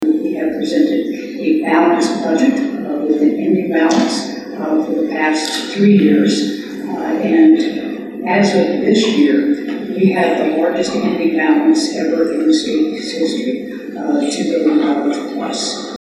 The Kansas Farm Bureau hosted its annual meeting Sunday, with Gov. Laura Kelly as the key guest speaker.
During a Q&A session after her speech, Kelly was asked about how the state is going to make up the funds without the tax.